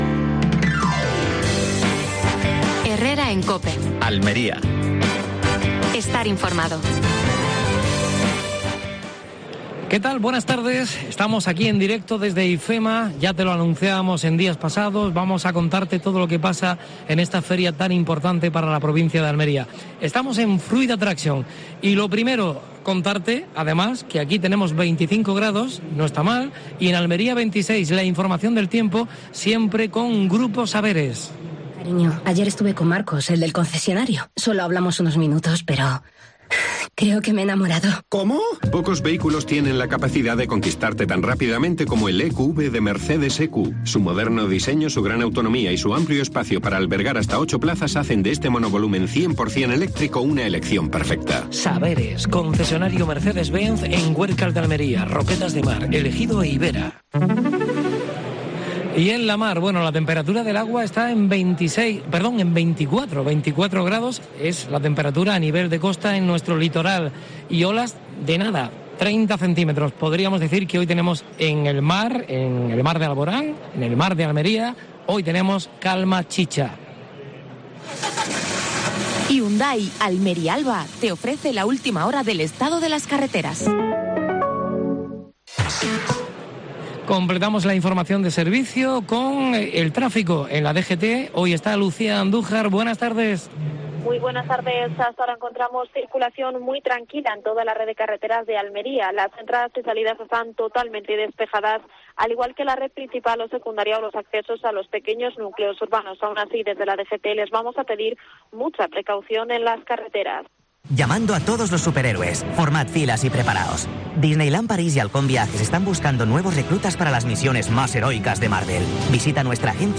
AUDIO: Programa especial desde Fruit Attraction (Madrid). Entrevista a María del Mar Vázquez (alcaldesa de Almería).